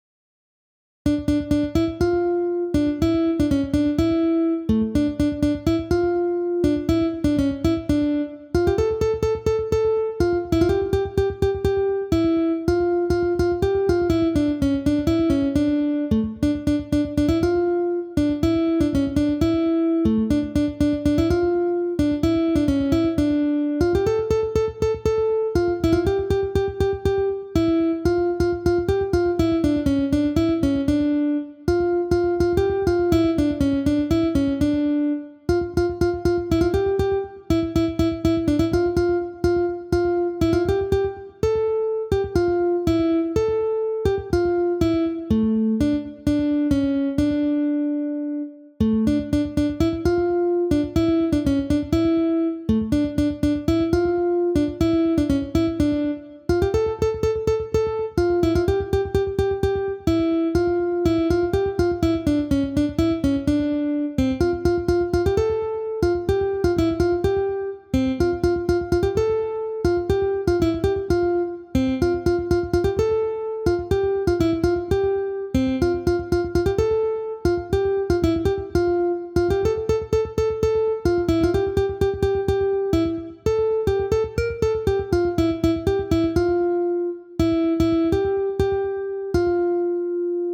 (приложу файл... слова убраны, только мелодия...)